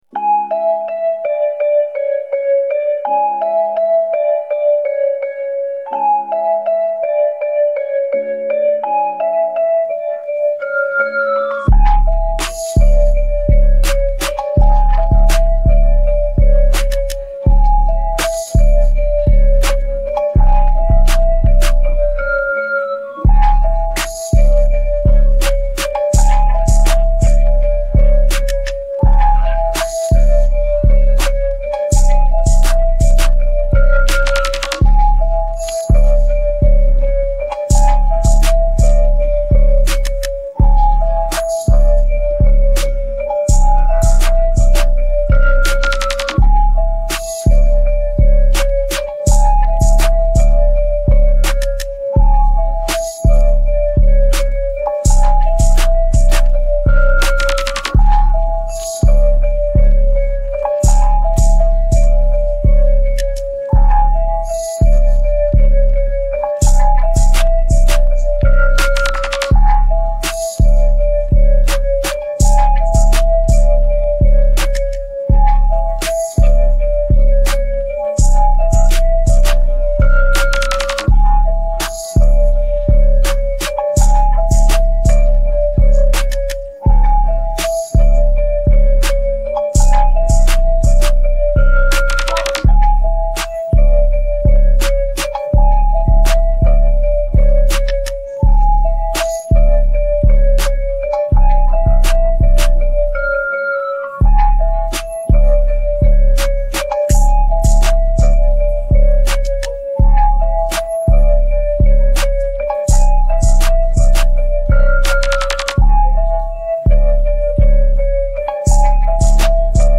Nhạc nền